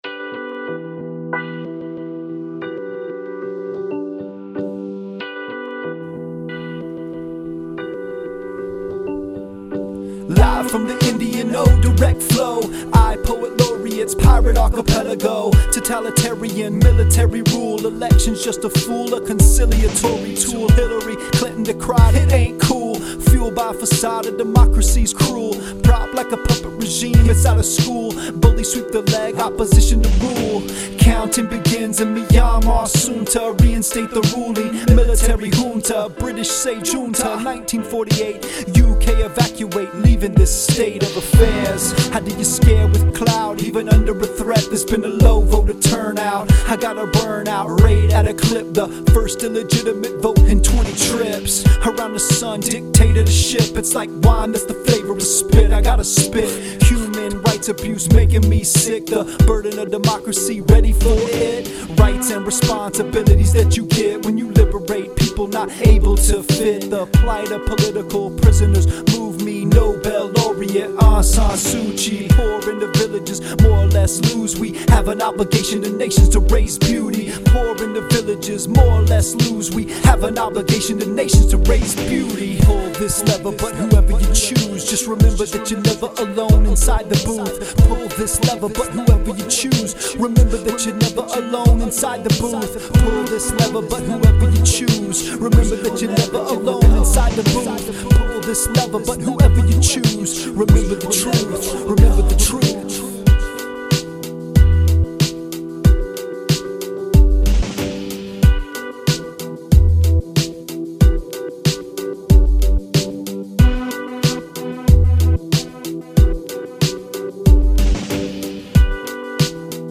Wrote a Myanmar rap on the way.